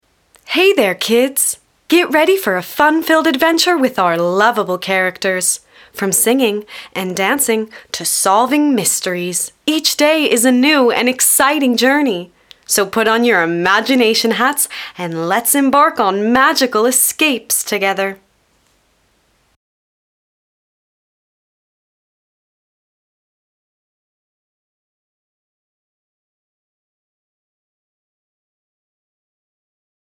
Bandes-son
Kids AD
- Basse
American-Standard (native)